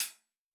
TC2 Live Hihat6.wav